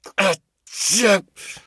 pain_8.ogg